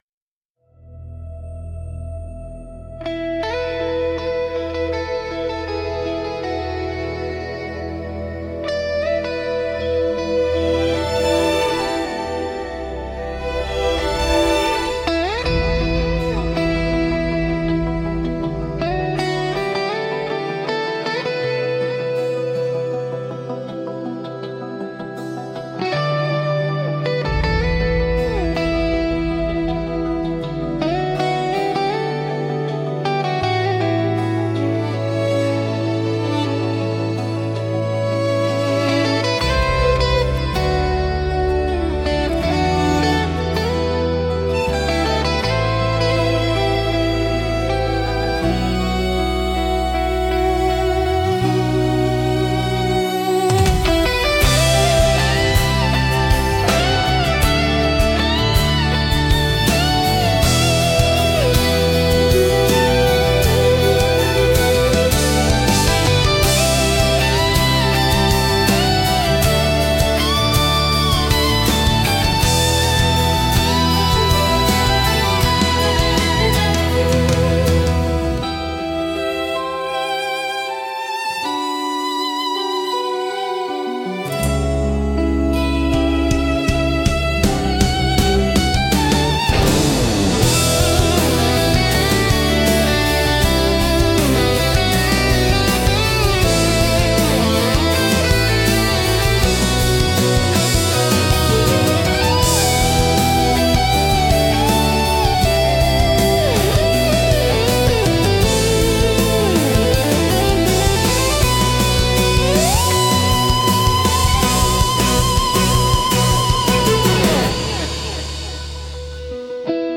聴く人に新鮮な発見と共感をもたらし、広がりのある壮大な空間を演出するジャンルです。